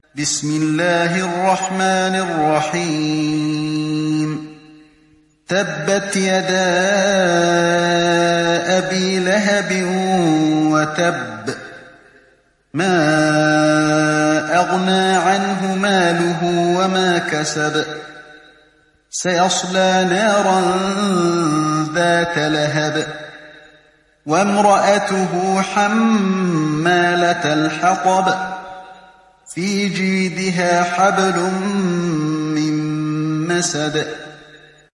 تحميل سورة المسد mp3 بصوت علي الحذيفي برواية حفص عن عاصم, تحميل استماع القرآن الكريم على الجوال mp3 كاملا بروابط مباشرة وسريعة